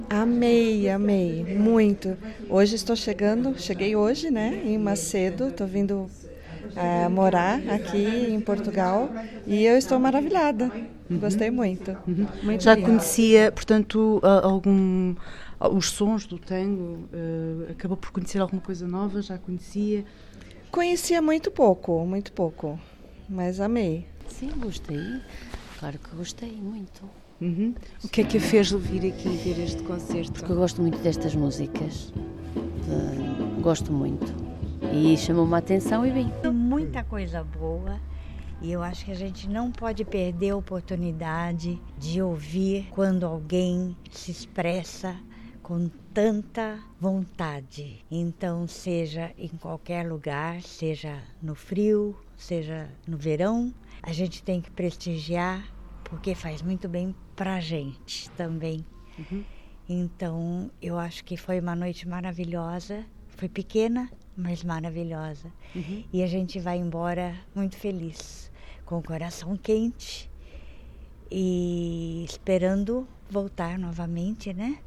Quem assistiu ficou maravilhado: